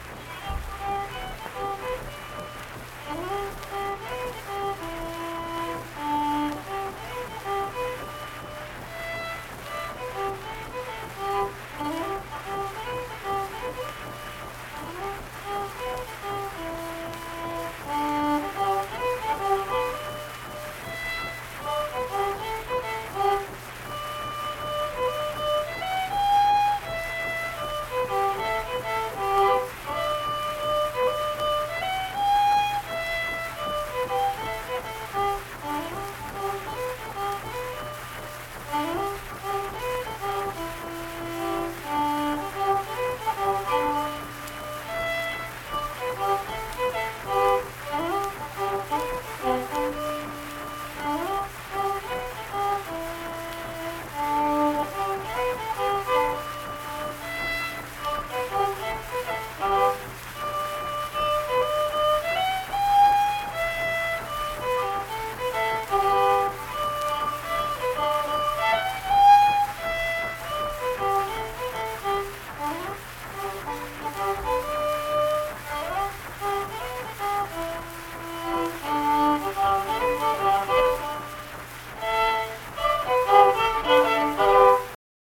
Unaccompanied fiddle music
Instrumental Music
Fiddle